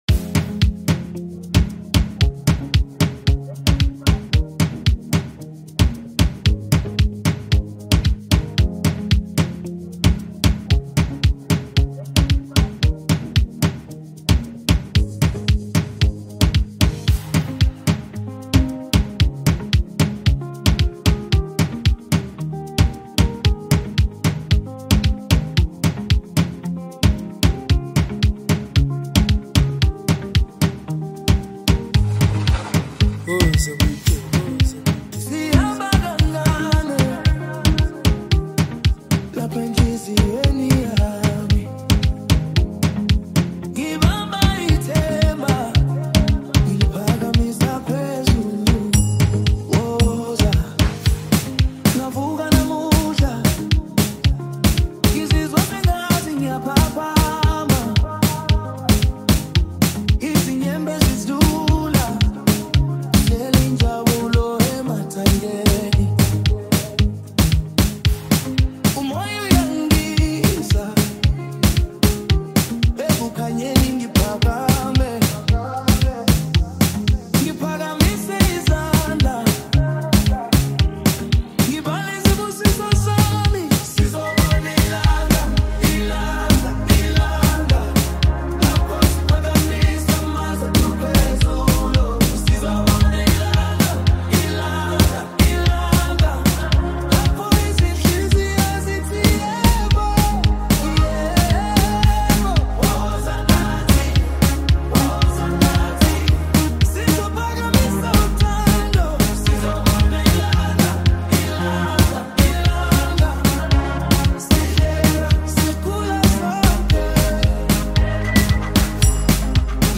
Home » Kwaito